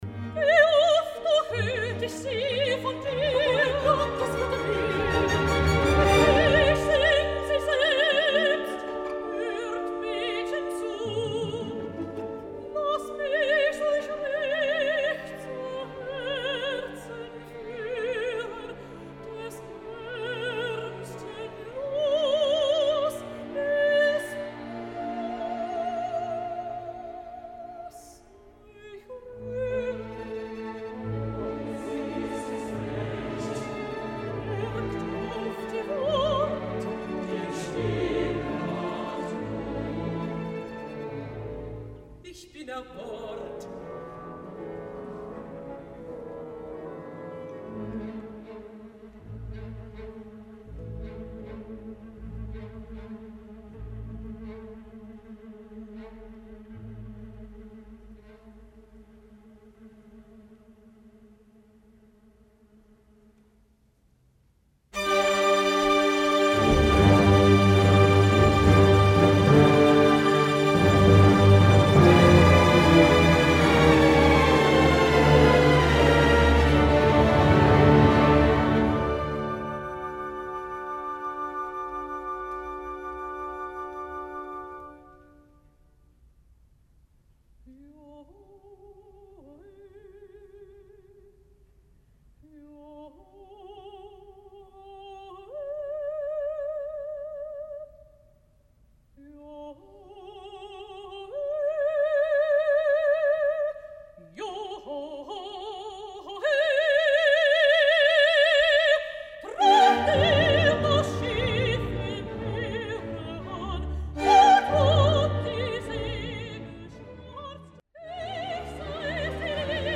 これが、初演の時のソプラノのウィルヘルミーネ・シュレーダー＝ドヴリアンにとっては辛かったようで、ワーグナーは彼女がもっと歌いやすくなるようにバラード全体を全音低いト短調に書き直しました。
それぞれにバラードが始まる少し前から始まり、バラードは途中をカット、そして最後から次のシーンに変わるとことまでになっています。